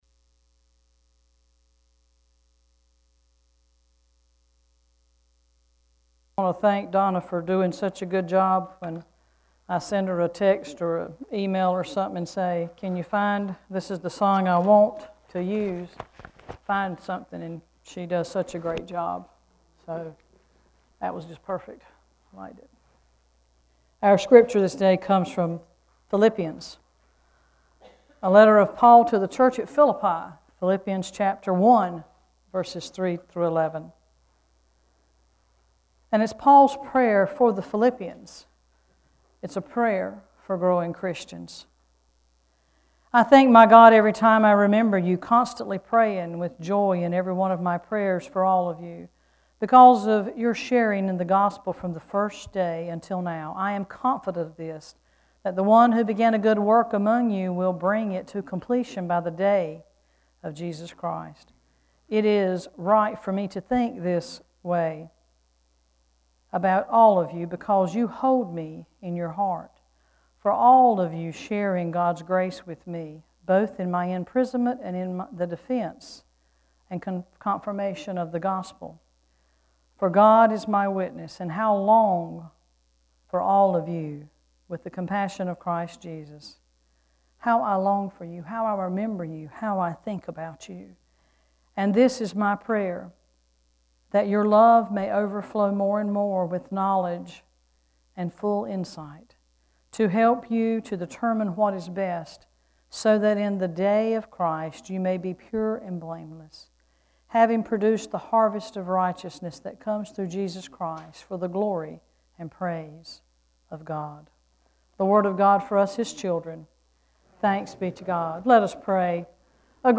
This weeks scripture and sermon:
12-6-scripture-and-sermon.mp3